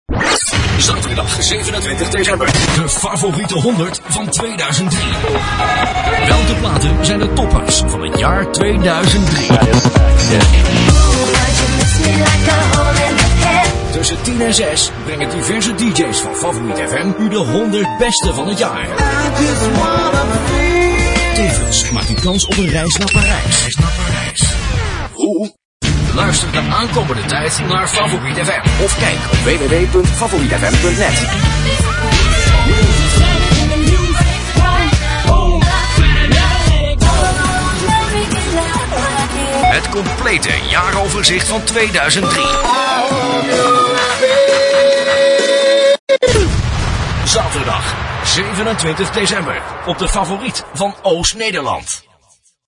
Op deze pagina tref je een aantal voorbeelden van jingles en promo's (in MP3 formaat) aan die vrij recentelijk door mij zijn ingesproken.